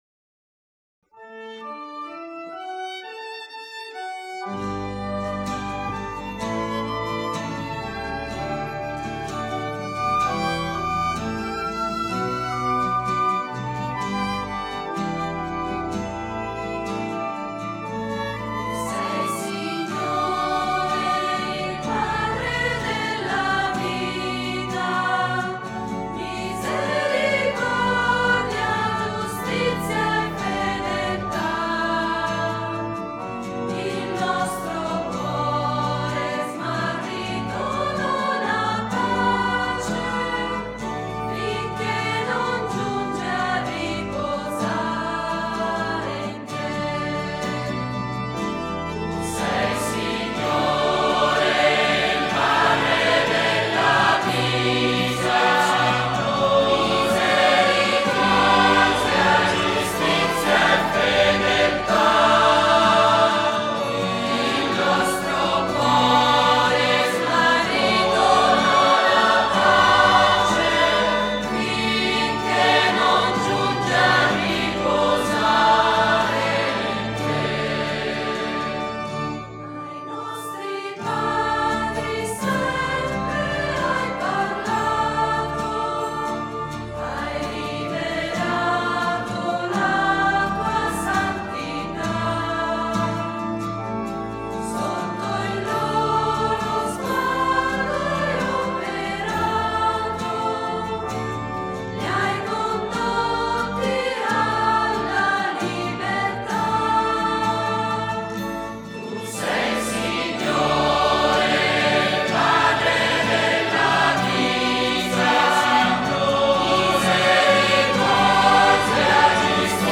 Organo
Violino
Chitarra
Percussioni
Soprani
Contralti
Tenori
Bassi